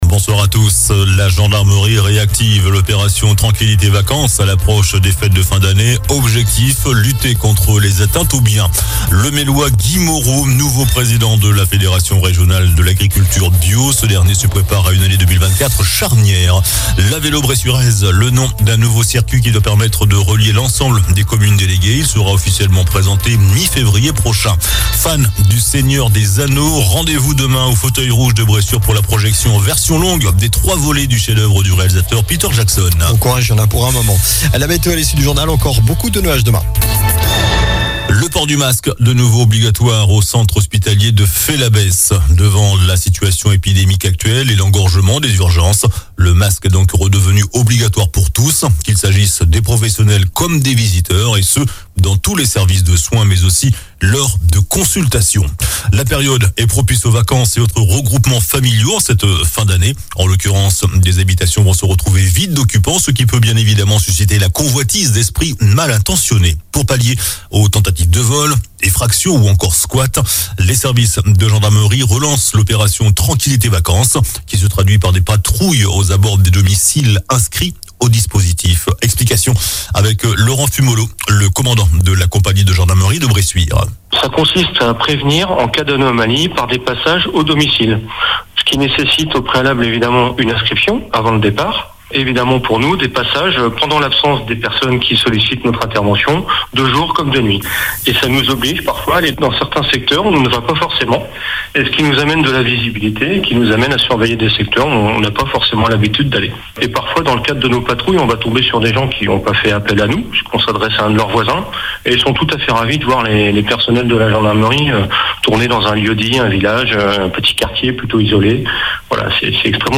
JOURNAL DU VENDREDI 22 DECEMBRE ( SOIR )